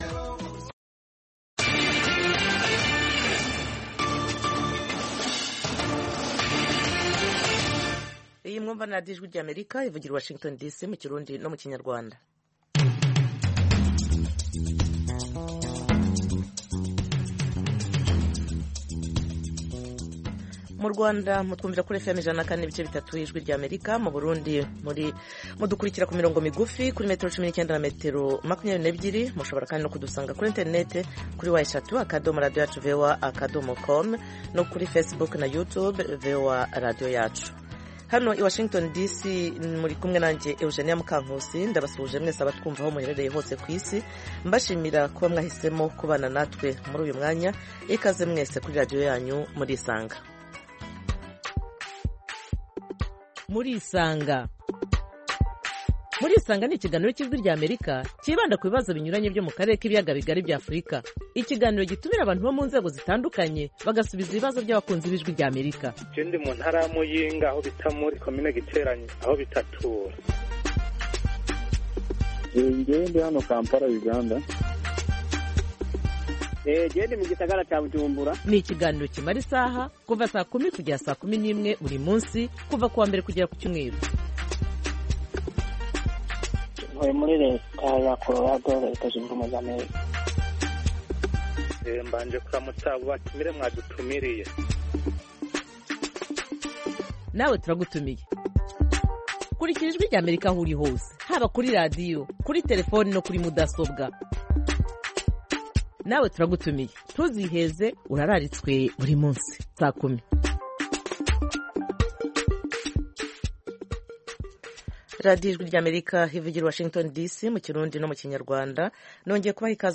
Gutwitacyangwa kubyara imburagihe bishobora kugira izihe ngaruka cyangwa guteza ibihe bibazo umwana w’umukobwa by’umwihariko ku buzima bwe cyangwa bw'umwana we? Abifuza gusobanuza no gusobanukirwa, ikaze mu kiganiro Murisanga,Twabatumiriye Dr. Odette Nyiramilimo.